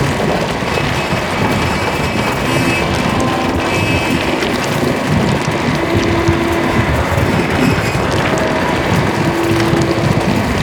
Sfx_creature_shadowleviathan_exoattack_loop_metal_and_water_layer_01.ogg